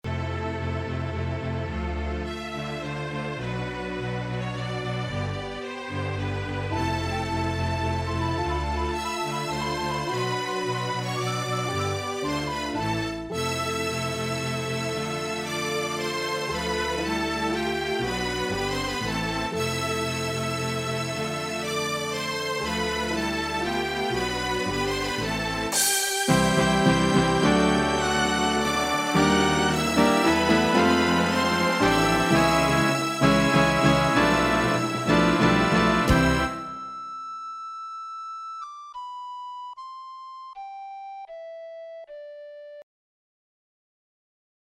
for orchestra plus recorder, guitar.   3 minutes.
Synthesized sample MP3 - 702K